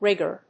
アクセント・音節ríg・ger
音節rig･ger発音記号・読み方rɪ́gər